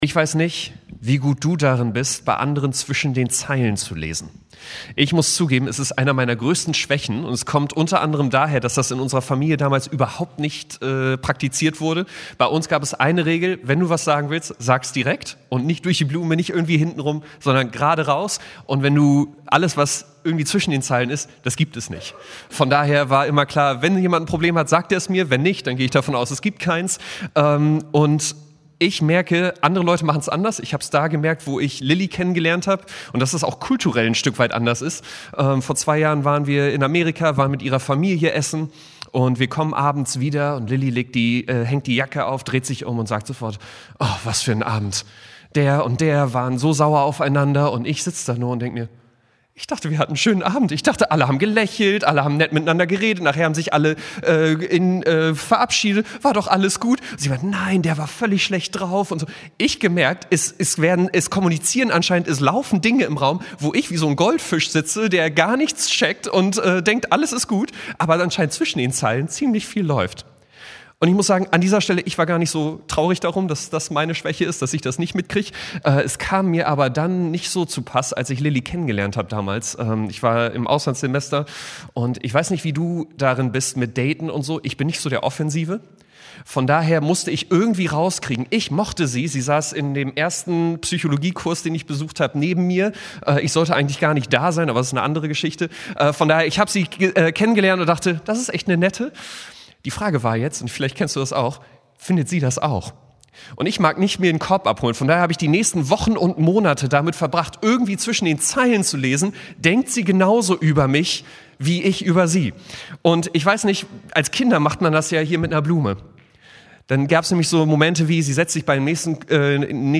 Serie: Standortgottesdienste